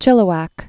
(chĭlə-wăk)